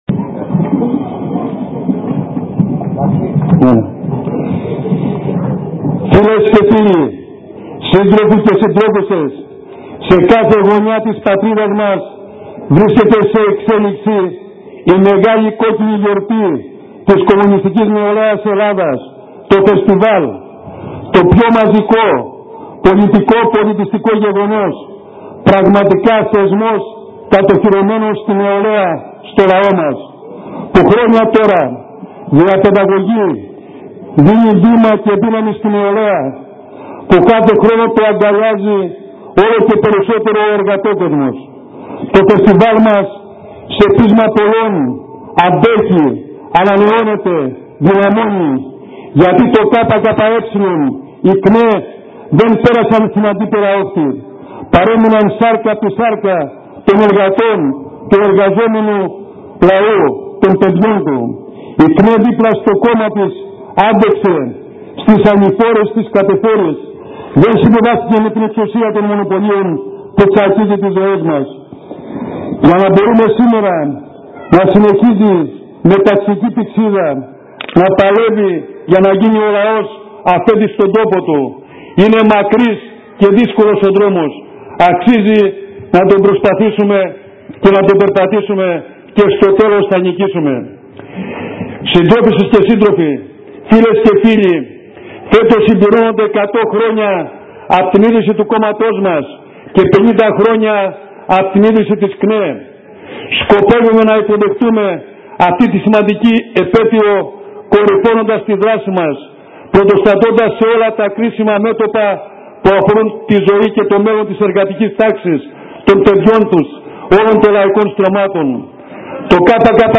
Κεντρικός ομιλητές ο Νίκος Μωραΐτης, βουλευτής Αιτωλοακαρνανίας του ΚΚΕ. Ο Βουλευτής στο περιθώριο του Φεστιβάλ μίλησε στο Kefalonia News για την ΚΝΕ αλλά και την πολιτική κατάσταση στη χώρα μας.